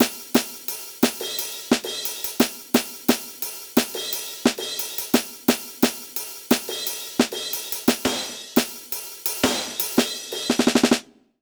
British ROCK Loop 175BPM (NO KICK).wav